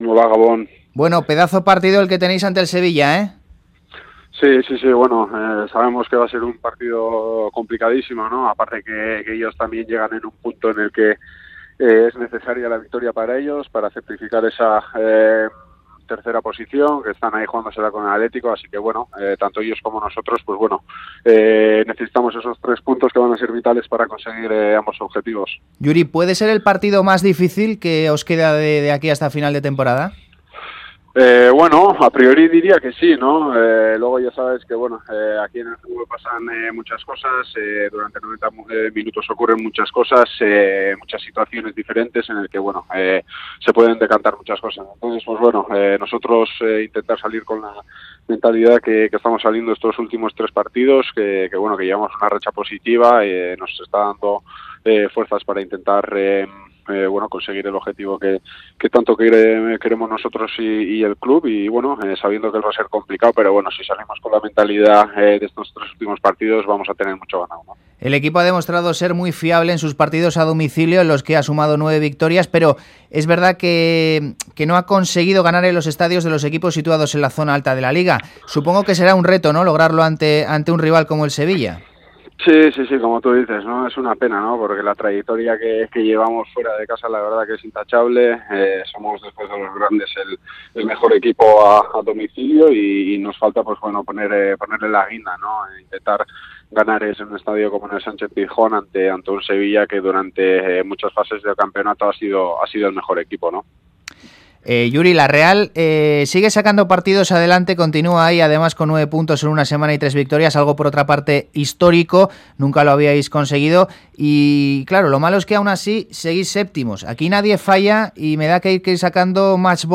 Audio: El lateral zurdo txuri urdin atiende la llamada de Fuera de Juego en la previa de enfrentarse al Sevilla en el Pizjuán.